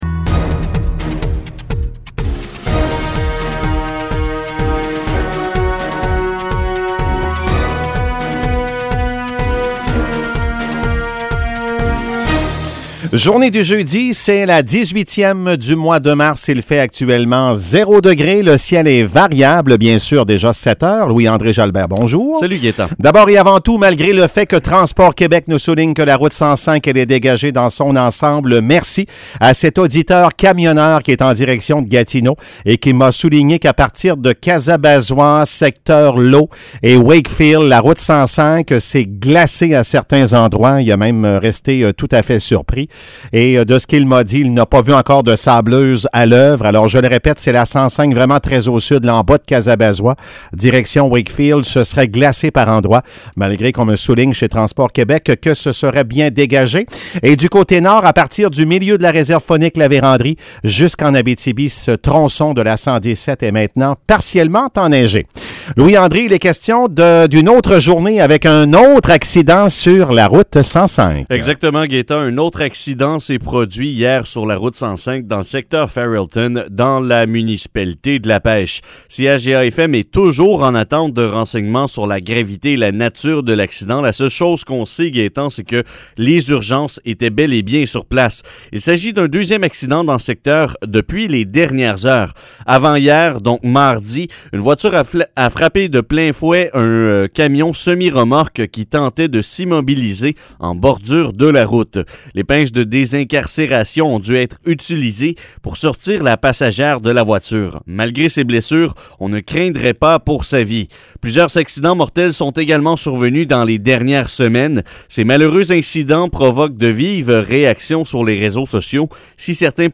Nouvelles locales - 18 mars 2021 - 7 h